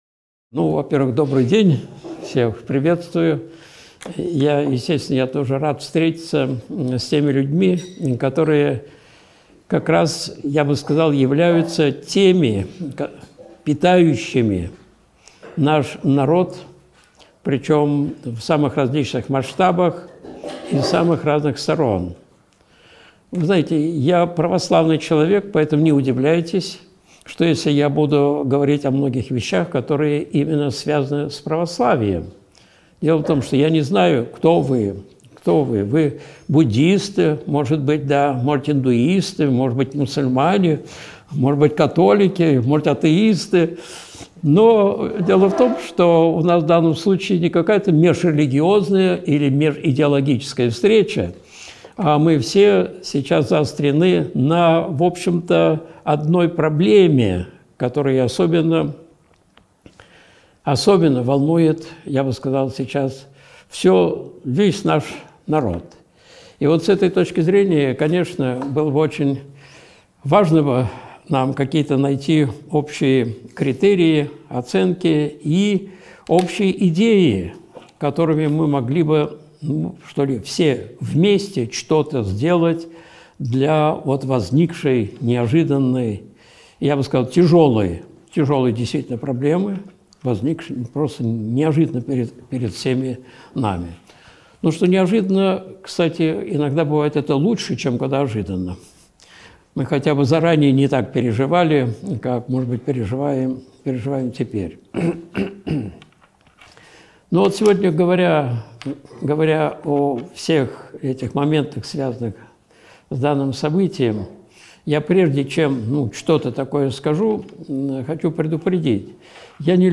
(Встреча с представителями СМИ, 22.03.2022)